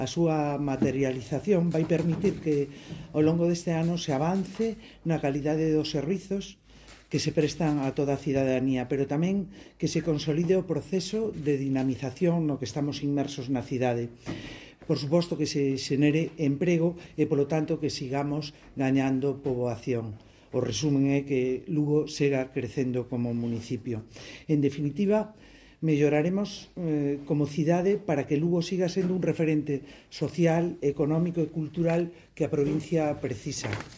La alcaldesa de Lugo, Paula Alvarellos, y el teniente de alcaldesa, Rubén Arroxo, presentaron esta mañana en el salón de plenos de la casa del Ayuntamiento el anteproyecto de Presupuestos municipales para 2025.